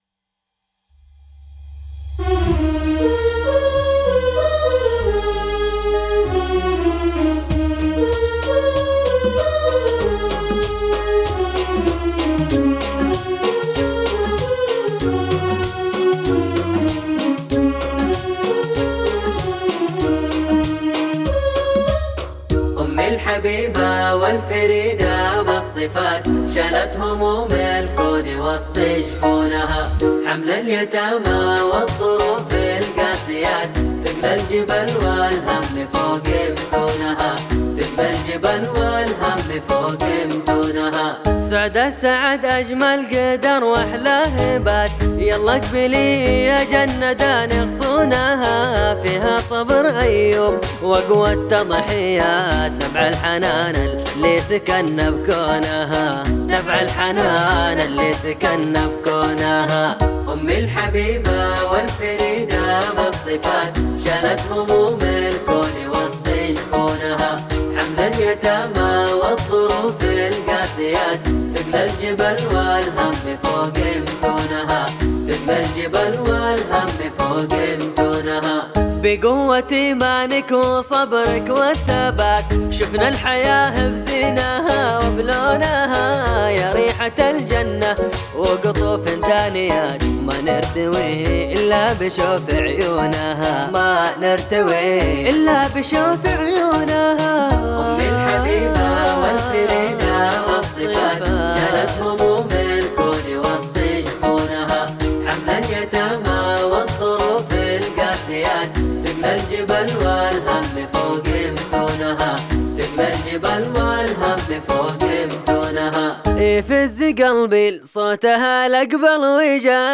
إيقاع